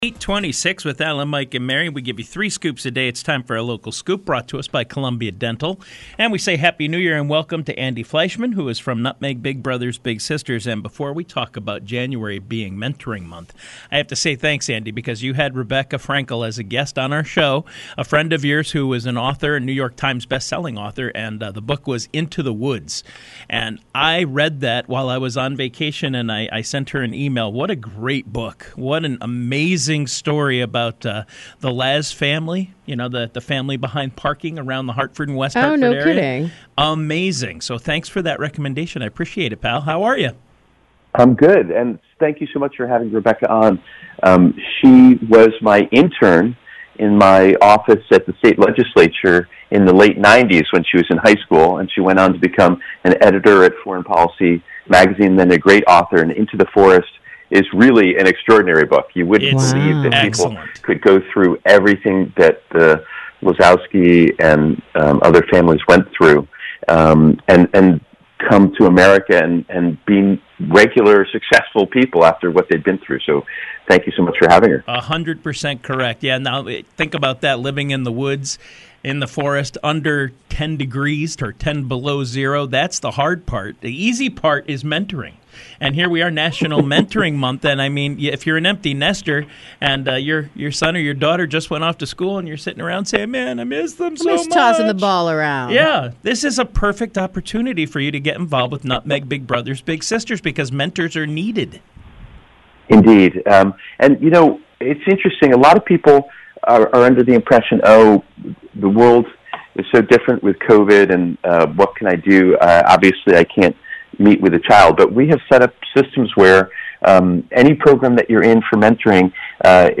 call-in interview guest on an early January edition of the WRCH drive-time show